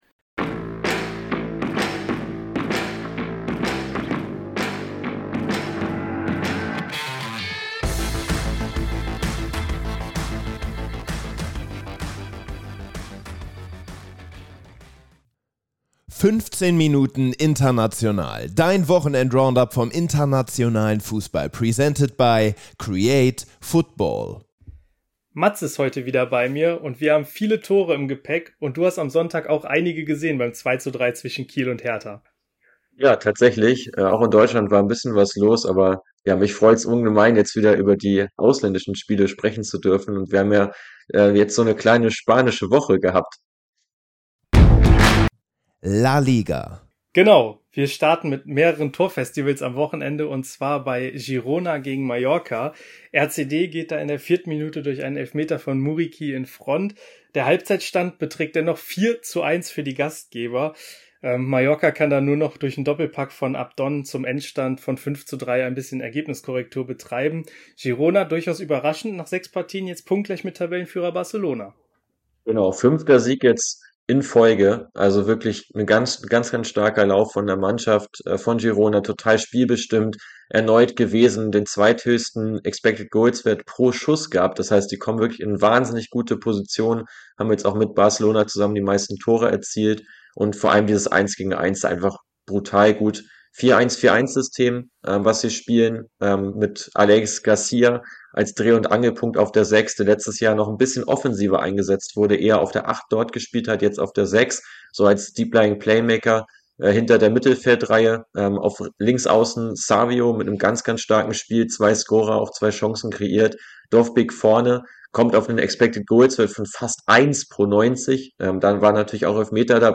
Ein Interview kommt selten allein!